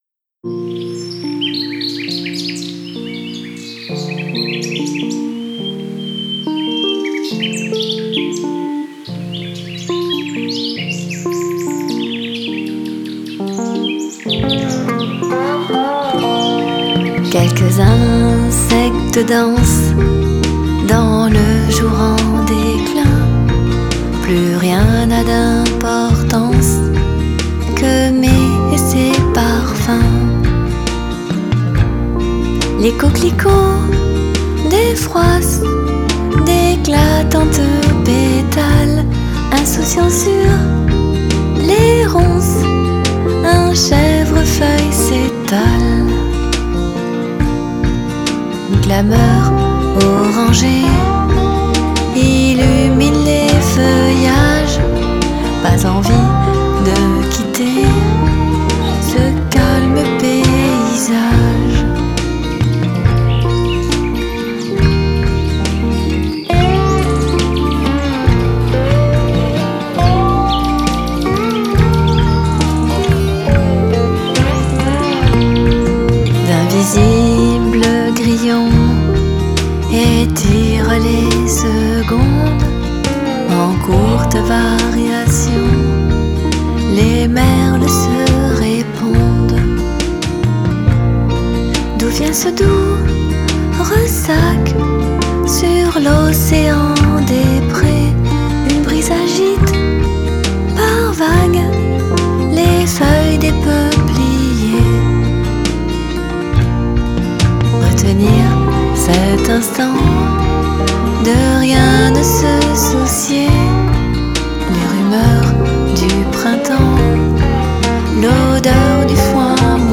Chansons folk et blues-rock en français
ballade bucolique folk.